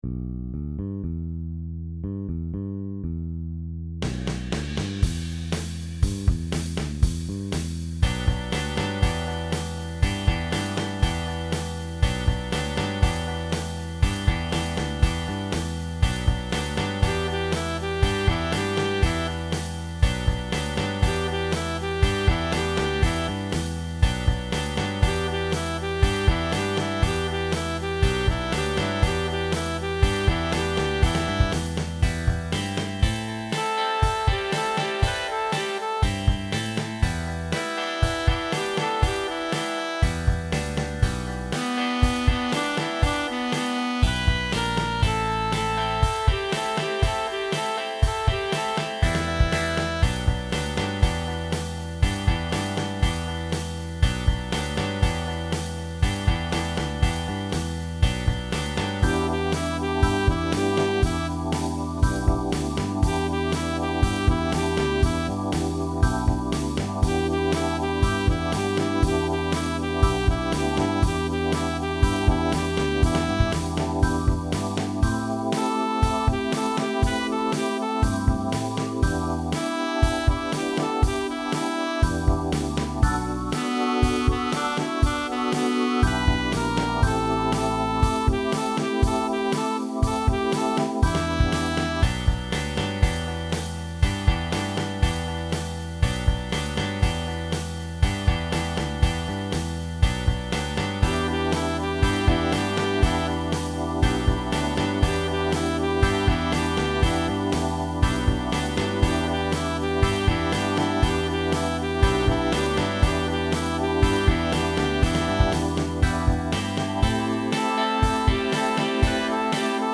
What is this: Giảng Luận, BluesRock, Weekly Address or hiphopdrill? BluesRock